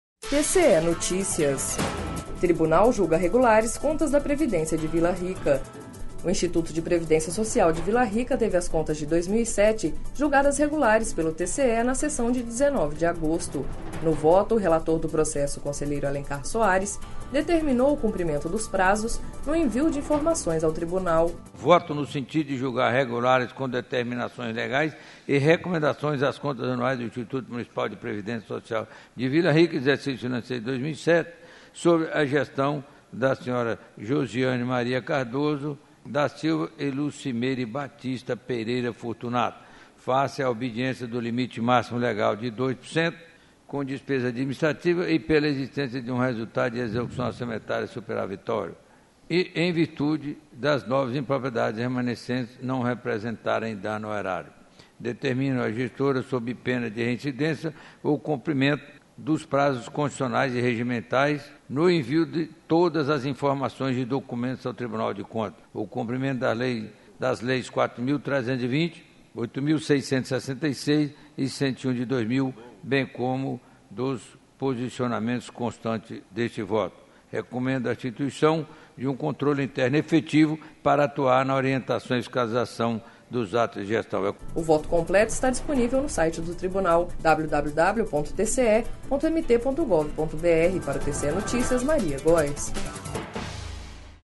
Sonora: Alencar Soares - conselheiro TCE- MT